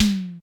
Drums_K4(10).wav